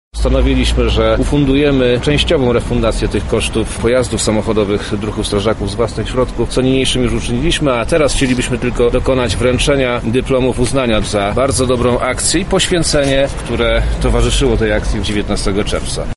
– mówi Wojewoda Lubelski Przemysław Czarnek.